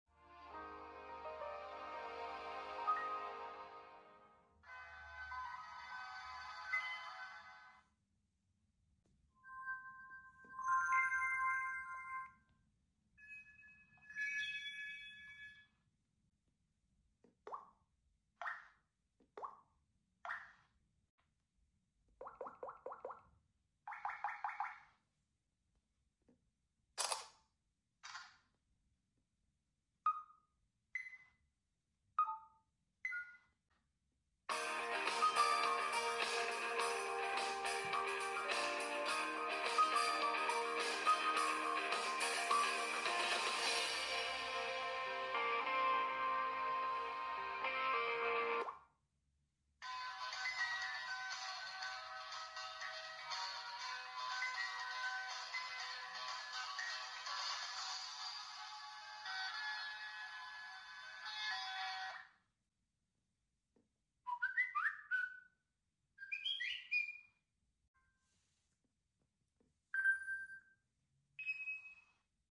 Talking Tom Cat recreates Android sound effects free download
Talking Tom Cat recreates Android 4.1.2 sounds